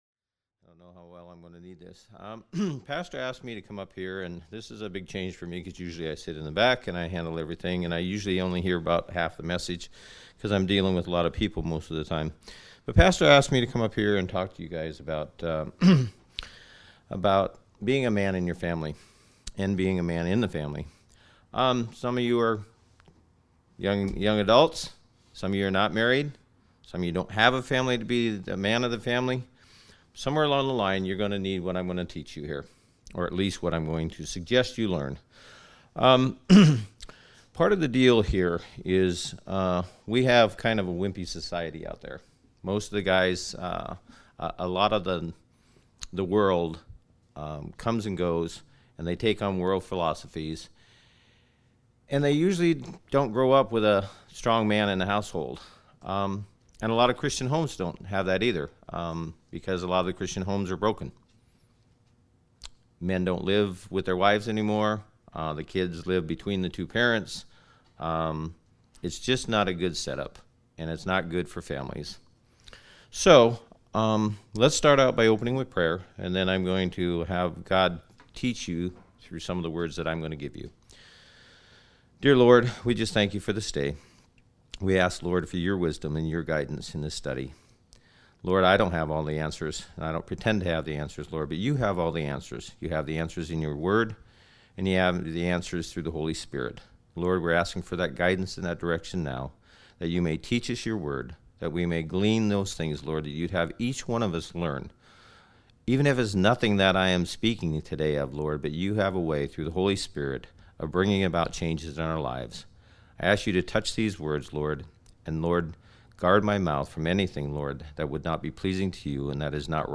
ODBC Audio Sermons